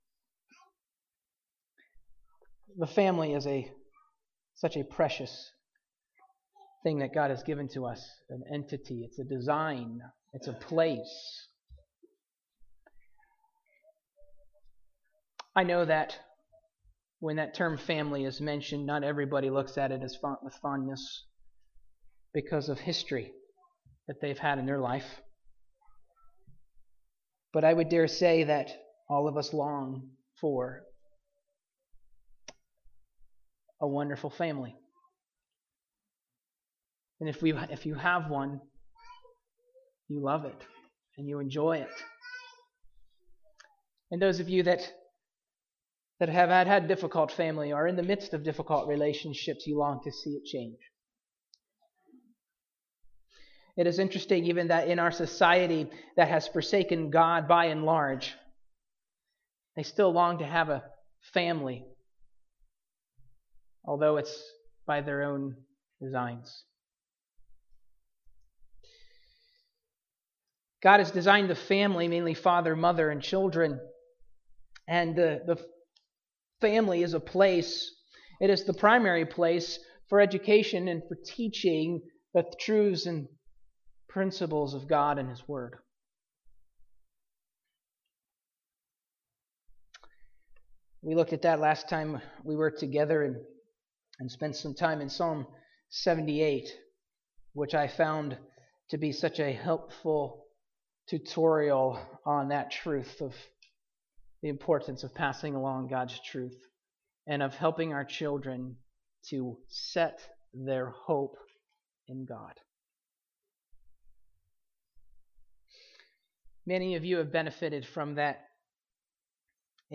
The Family Passage: 2 Timothy 3:14-15, Deuteronomy 6:1-9, Ephesians 2:1-10 Service: Sunday Morning